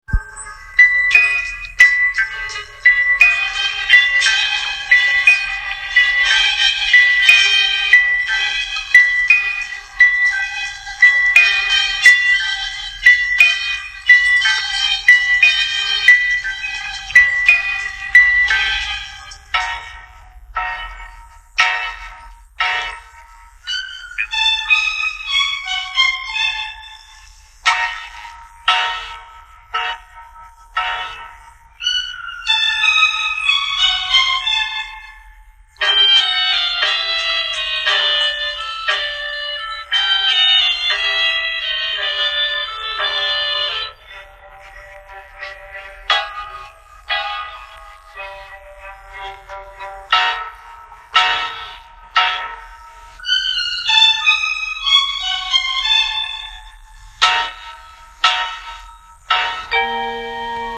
Тревожная мелодия
Друзья!  Помогите, пожалуйста, опознать эту тревожную мелодию